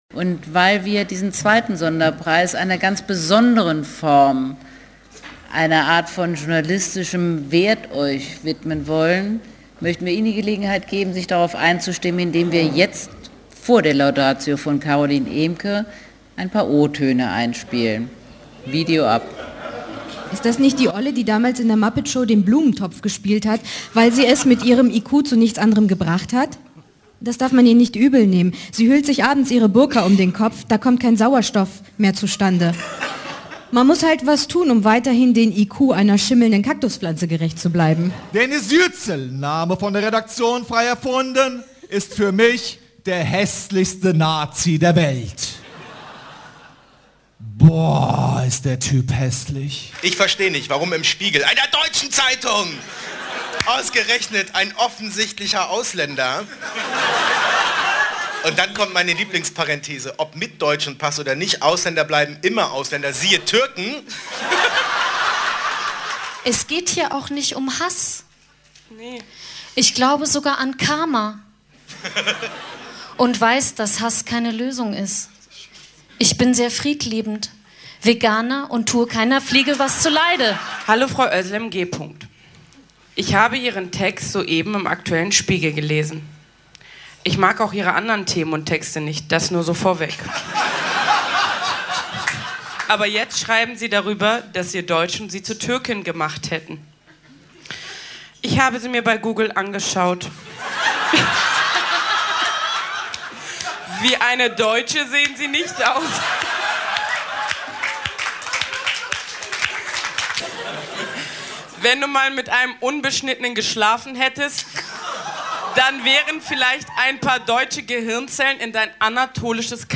Preisverleihung
Laudatio: Carolin Emcke, freie Publizistin
Wo: Berlin, Deutsches Historisches Museum